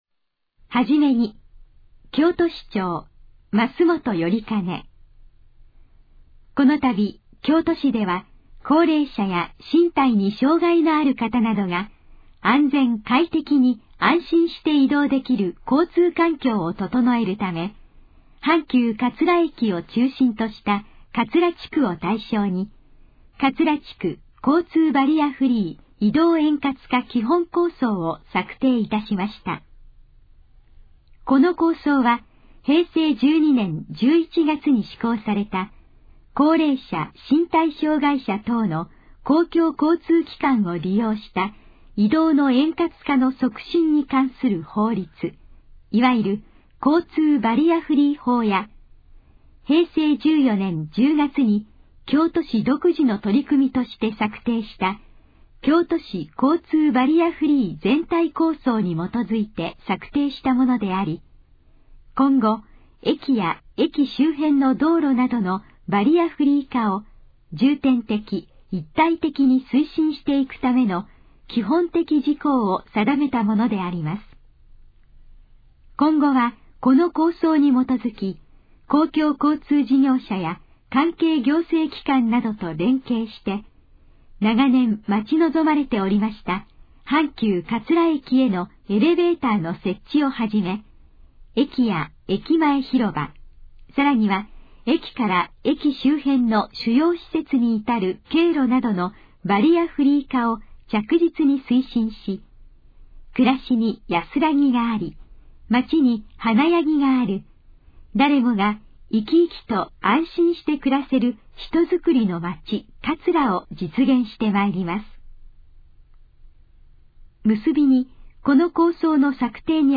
このページの要約を音声で読み上げます。
ナレーション再生 約292KB